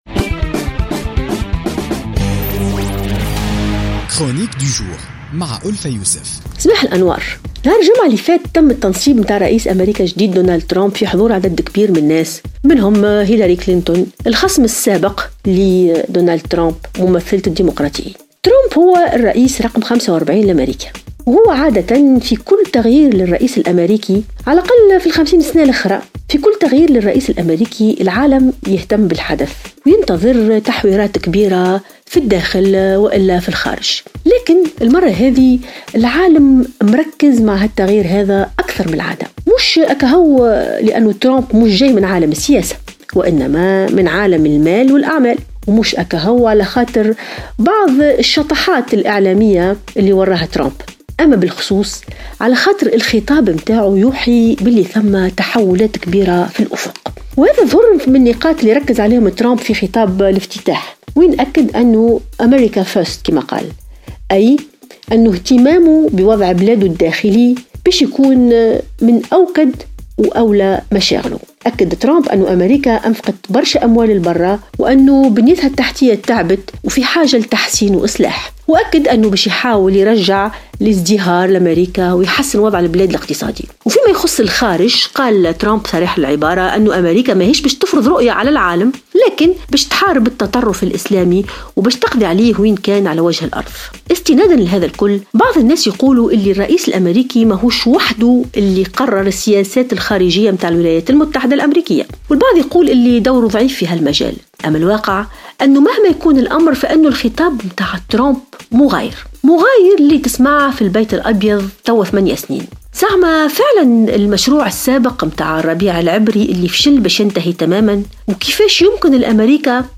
تطرقت الكاتبة ألفة يوسف في افتتاحية اليوم الثلاثاء إلى حفل تنصيب دونالد ترامب وما رافقه من جدل وحديث مشيرة إلى أن هذا اللغو طبيعي ورافق كل تغيير لرئيس أمريكا.